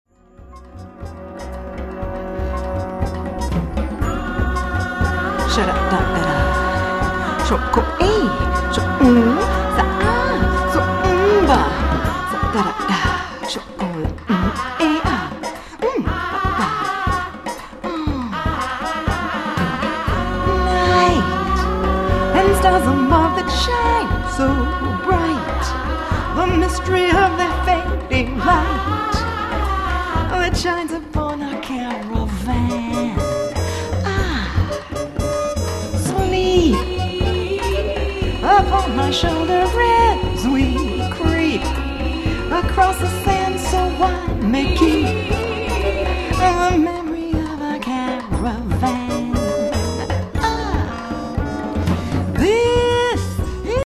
Jazz Vocalist
All tracks are short samples.
vocals (5, 8, 13, 14)
drums (All tracks except 6), shaker, bells, tabla (5),
soprano saxophone (2, 13), flute (5, 6, 9, 10, 14, 15),